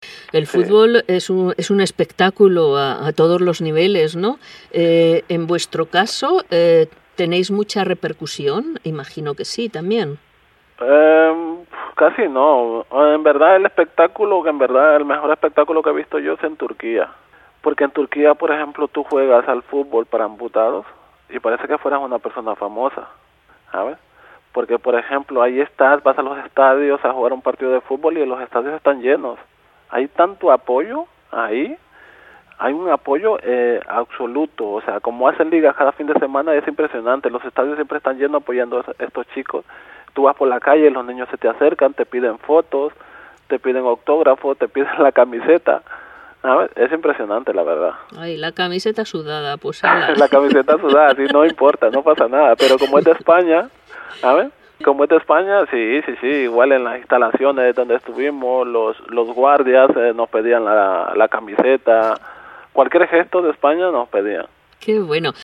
con entusiasmo formato MP3 audio(1,25 MB)